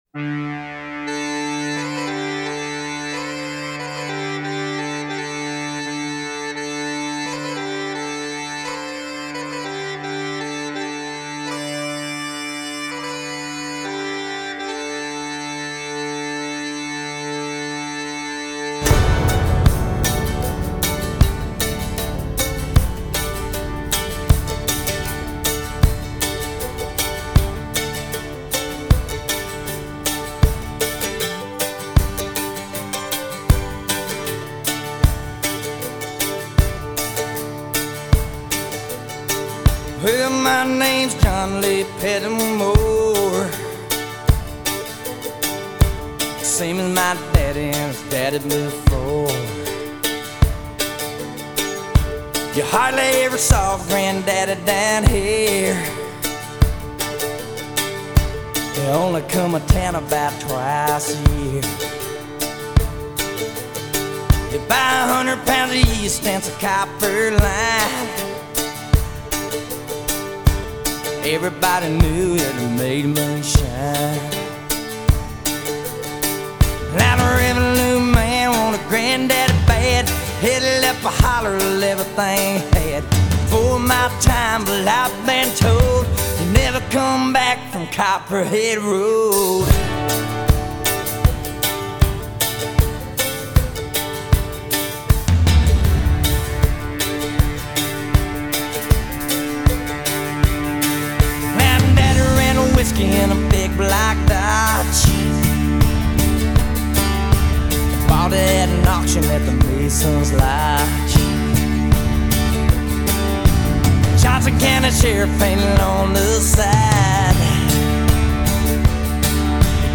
Трек размещён в разделе Зарубежная музыка / Кантри.